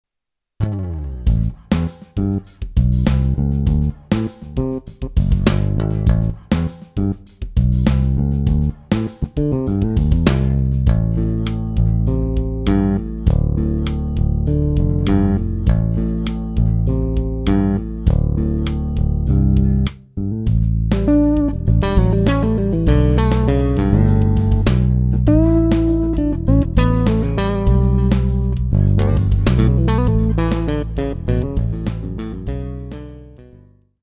All of the samples were DI'd (direct injected) for sampling.
Fingered
The Fingered soundset is similar to the Bassics sound, however, it was designed with "finer" bass playing in mind and gives you very clean sounding bass lines and solos. The strings were plucked as close to the bridge as possible.